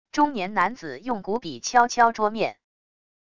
中年男子用骨笔敲敲桌面wav音频